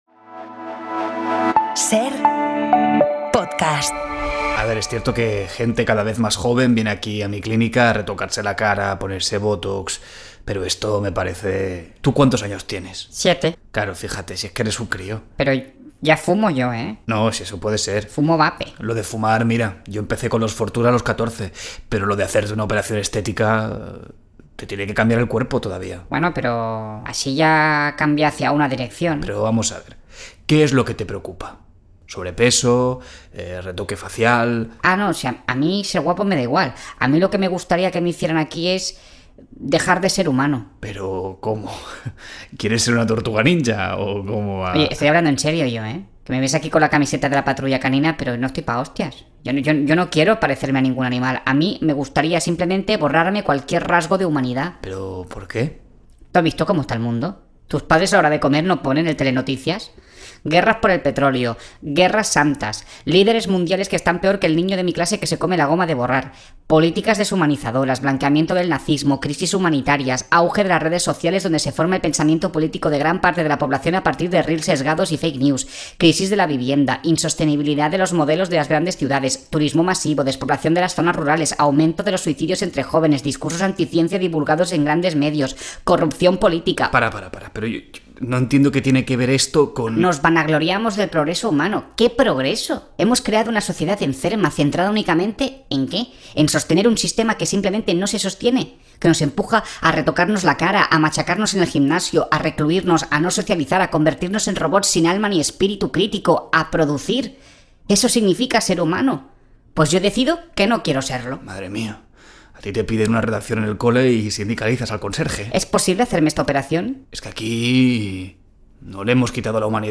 Audio de Humor en la Cadena SER en Podium Podcast
Manuel Burque sale a la calle para decantar la guerra entre crucigramas, sudokus, autodefinidos y sopas de letras.